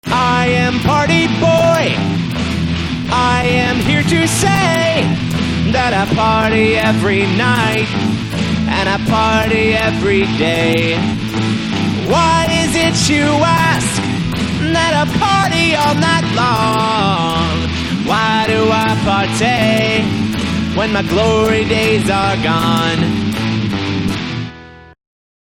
i'm producing a new album that has more of a rock influence than my recent solo works! the idea i had was that i wanted to make an record where i played all the instruments. a majority of the tracks in my discography are sequenced. ill post updates here and tracks as they are completed.
this is a quickly-made demo and the mixing is not the best but the point is that i played all the instruments
sounds good so far
why do you autotune yourself so much